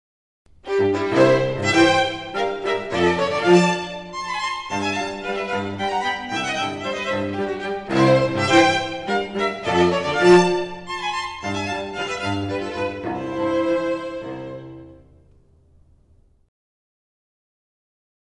Their repertoire spans the baroque, classical, romantic, and modern eras and includes jazz and pop classics, opera excerpts, and some ragtime.